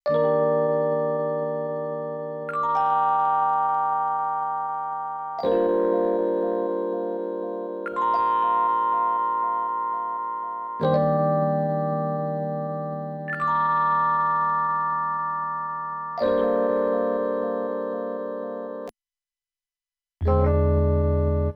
03 rhodes AB.wav